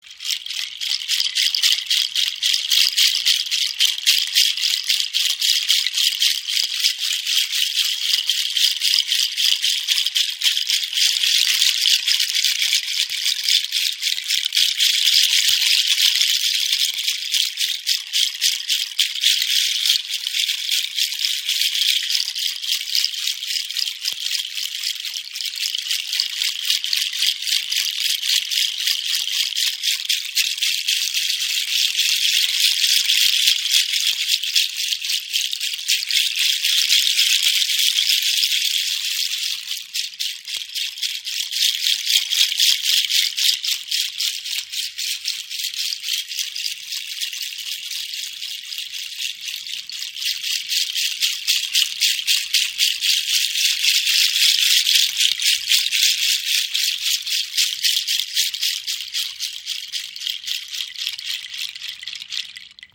Rassel | XL Hand-Shaker | Kenari-Samen im Raven-Spirit WebShop • Raven Spirit
Klangbeispiel
Hand Shaker aus besonders vielen Kenari Samen. Erzeugt einen kräftigen, durchdringenden Klang und ist dabei vielseitig einsetzbar.